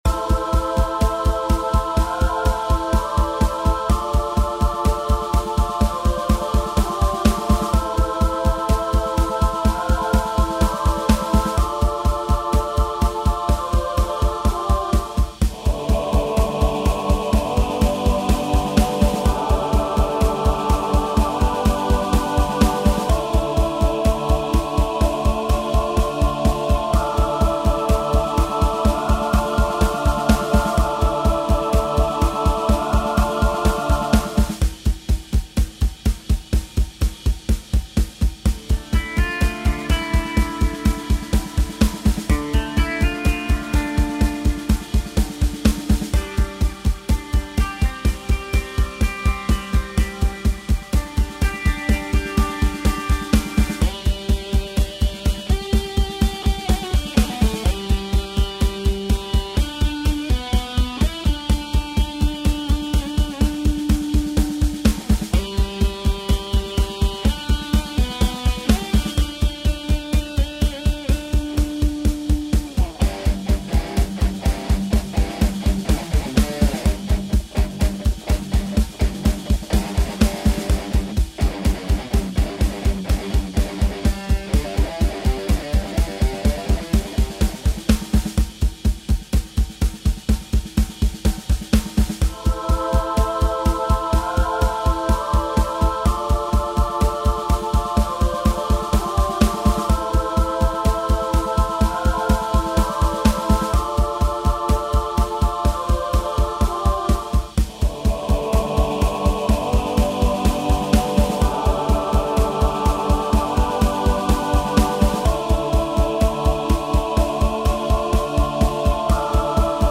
Home > Music > Rock > Bright > Dreamy > Running